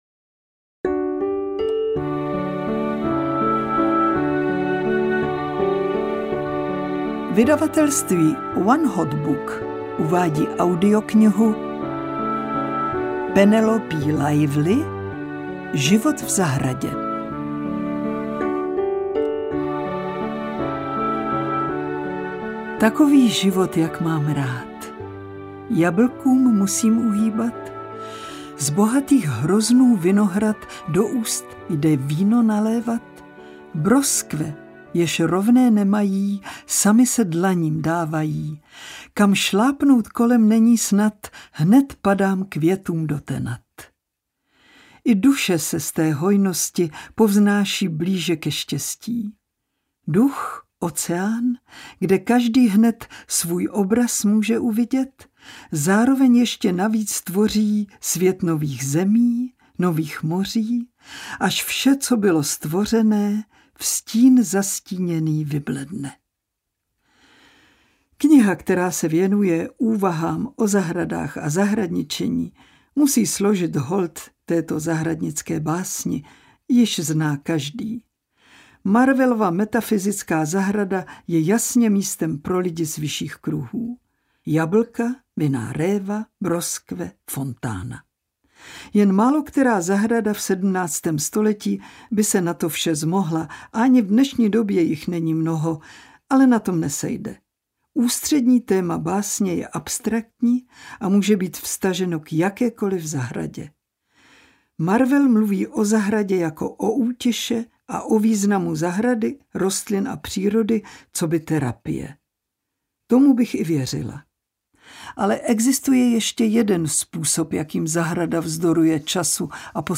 Život v zahradě audiokniha
Ukázka z knihy
• InterpretLibuše Švormová